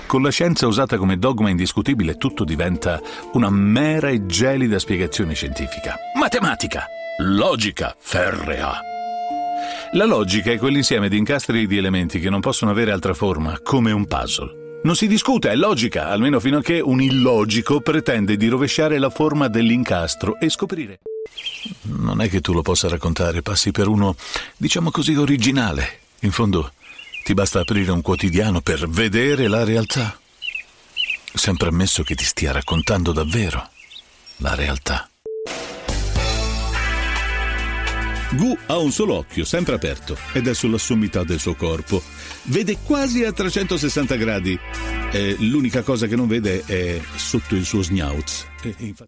Weltweit bekannte Marken vertrauen ihm und er liefert professionelle Voiceover-Dienste mit Wärme, Klarheit und Schnelligkeit aus seinem hochmodernen Studio.
UAD Apollo X8, Mac Pro, U87Ai, TLM103, TLM67 und mehr.